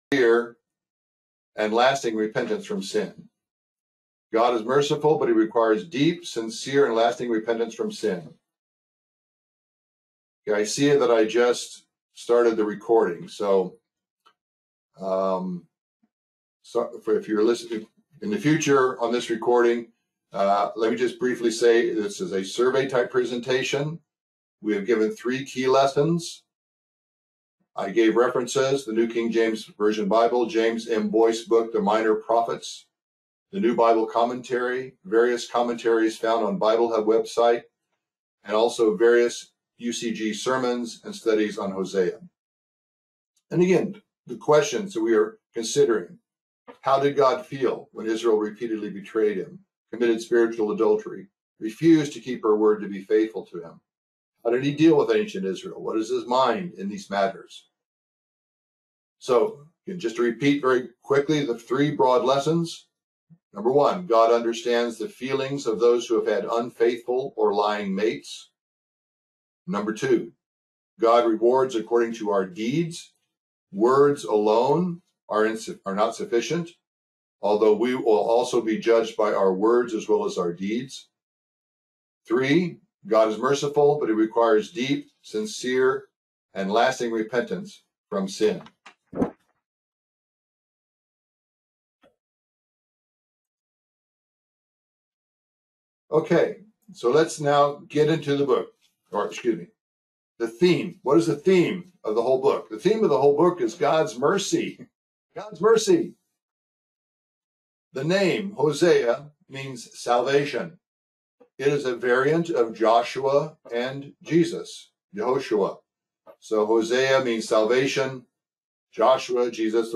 Bible Study: Hosea 1-6